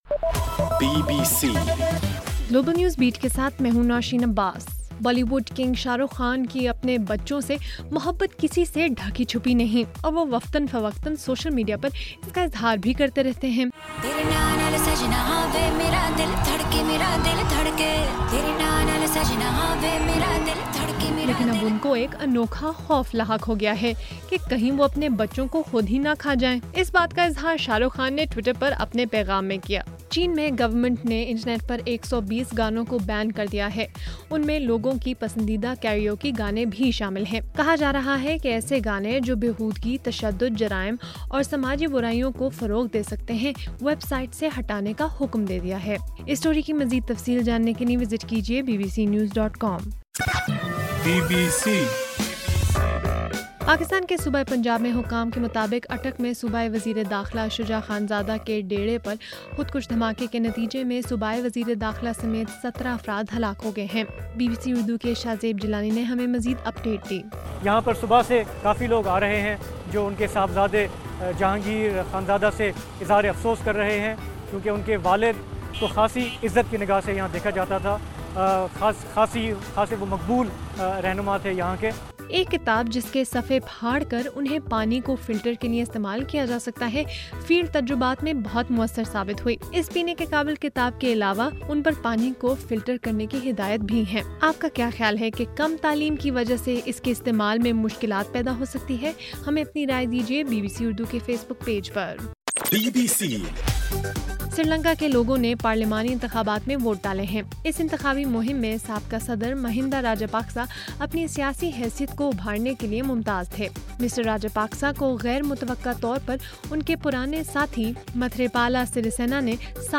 اگست 17: رات 10 بجے کا گلوبل نیوز بیٹ بُلیٹن